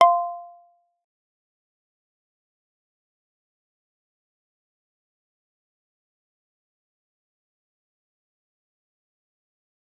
G_Kalimba-F5-f.wav